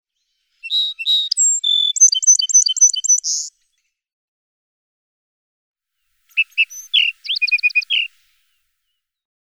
Sage thrasher
First a normal song sparrow song, and then two snippets of thrasher song rearranged to reveal the thrasher's hurried mimicry.
Marble Hot Springs, Sierra Valley, California.
114_Sage_Thrasher.mp3